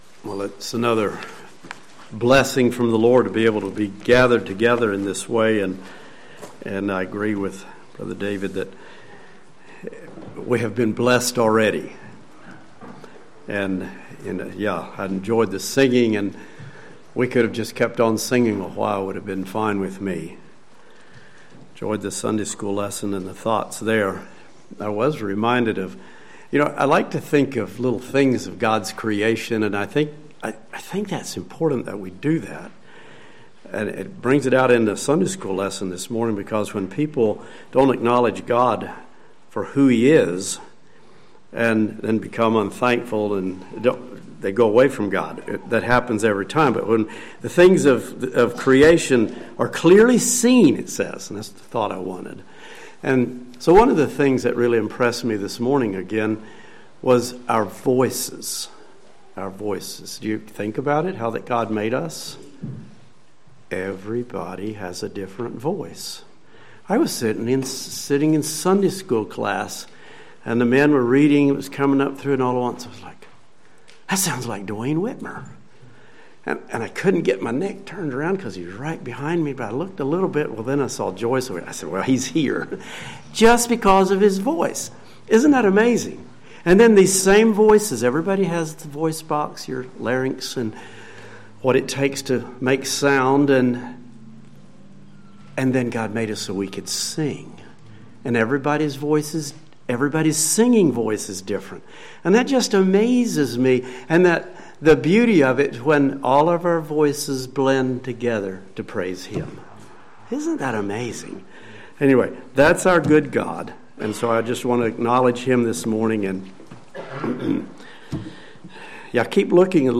Sermons
Life in Christ | Bible Conference 2026